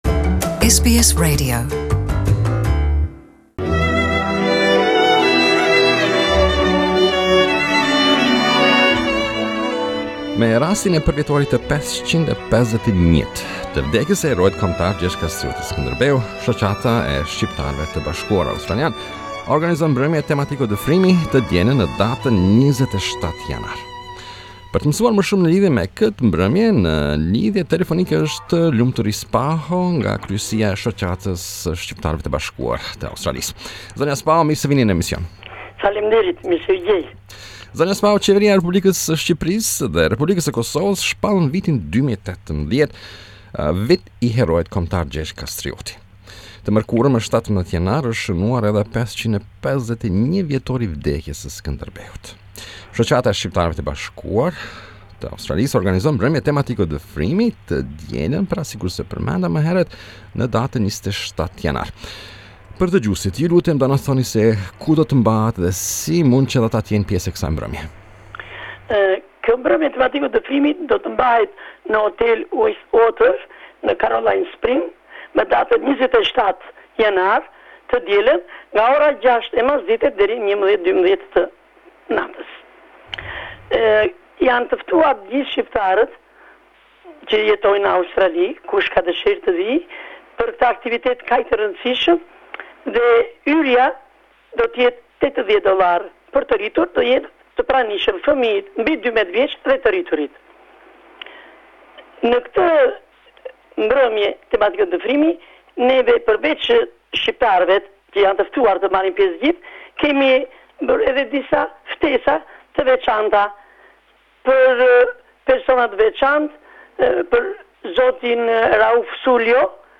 The United Albanians of Australia Association has organised an evening to honour our National Hero, Gjergj Kastrioti Skerndebeu. We interviewed one of the organisers